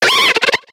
Cri de Feuiloutan dans Pokémon X et Y.